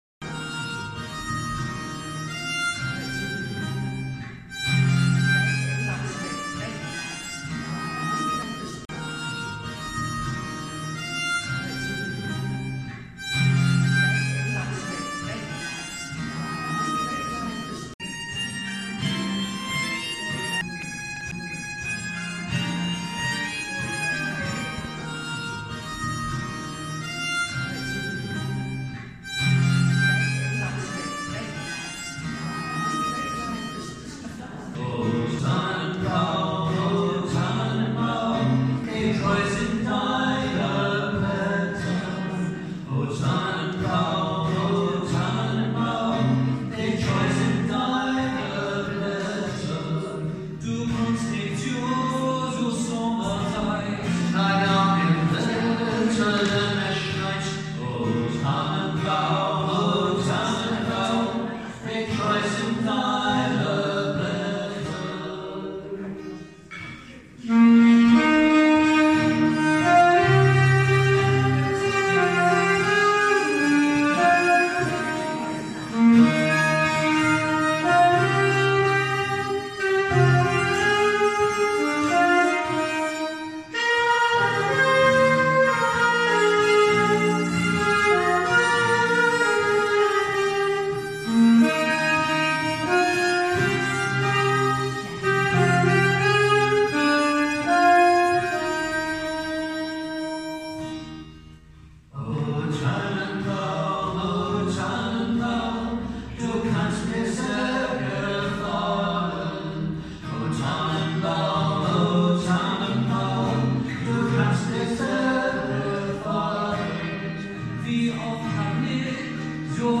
Concerts with Band of Brothers and Family